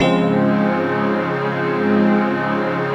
DM PAD5-6.wav